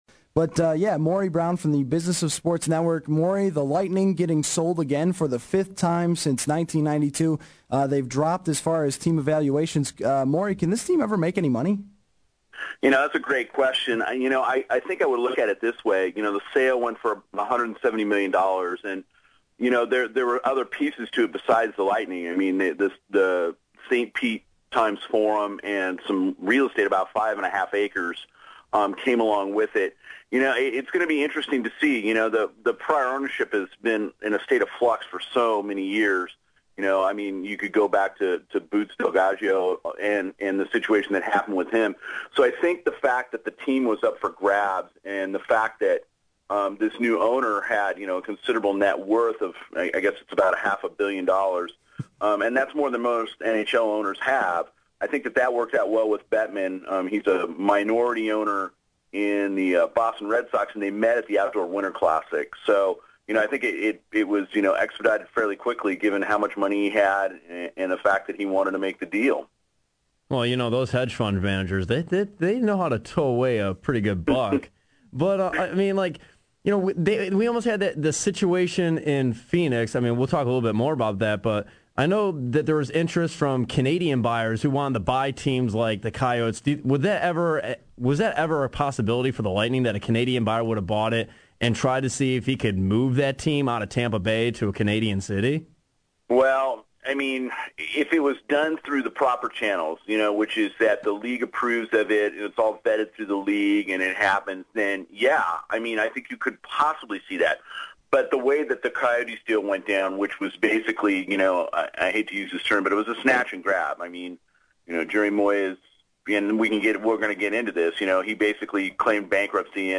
Audio courtesy of SportsRadio 950 ESPN in Rodchester